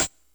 Hat (23).wav